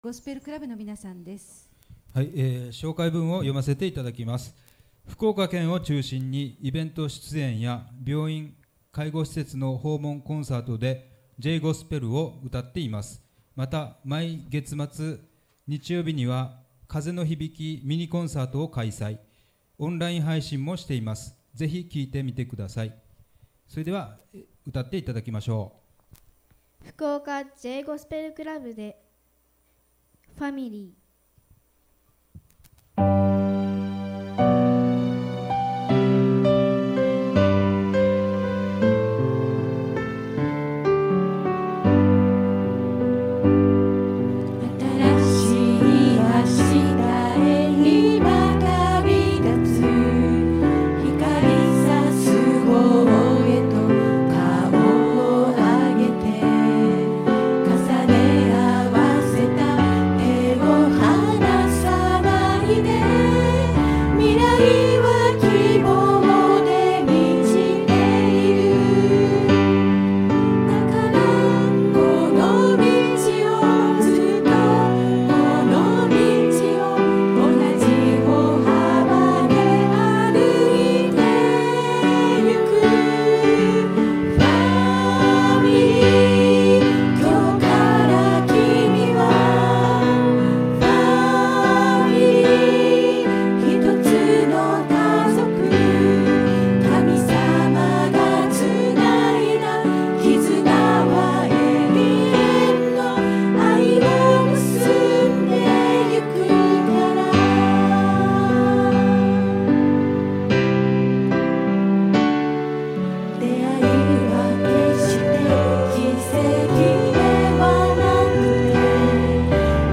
2019年JGospelコンテスト九州地区決勝大会をYouTubeと音声で